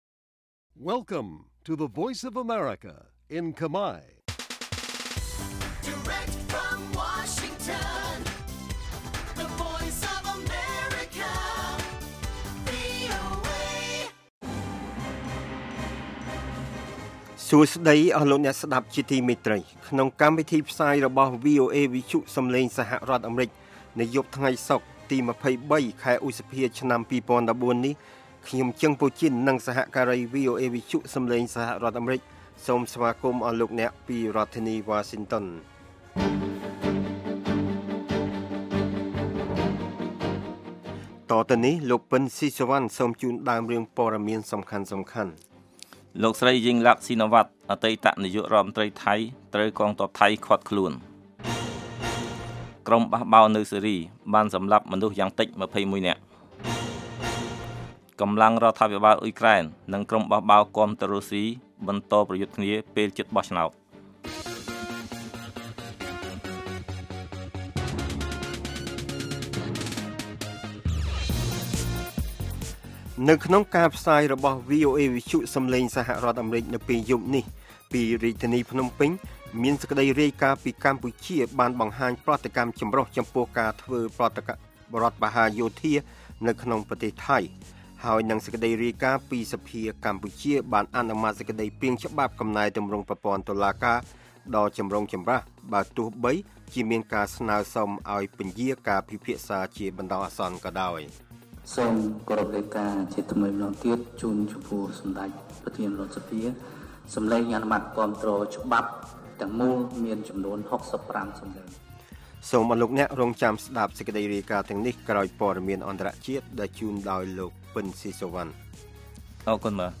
នេះជាកម្មវិធីផ្សាយប្រចាំថ្ងៃតាមវិទ្យុ ជាភាសាខ្មែរ រយៈពេល ៦០ នាទី ដែលផ្តល់ព័ត៌មានអំពីប្រទេសកម្ពុជានិងពិភពលោក ក៏ដូចជាព័ត៌មានពិពណ៌នា ព័ត៌មានអត្ថាធិប្បាយ កម្មវិធីតន្ត្រី កម្មវិធីសំណួរនិងចម្លើយ កម្មវិធីហៅចូលតាមទូរស័ព្ទ និង បទវិចារណកថា ជូនដល់អ្នកស្តាប់ភាសាខ្មែរនៅទូទាំងប្រទេសកម្ពុជា។ កាលវិភាគ៖ ប្រចាំថ្ងៃ ម៉ោងផ្សាយនៅកម្ពុជា៖ ៨:៣០ យប់ ម៉ោងសកល៖ ១៣:០០ រយៈពេល៖ ៦០នាទី ស្តាប់៖ សំឡេងជា MP3